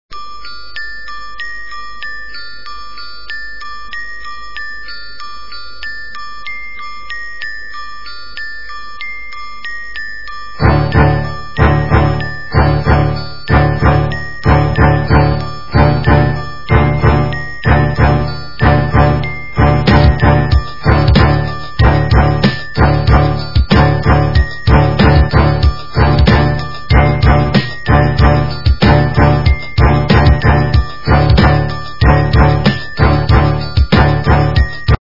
- рэп, техно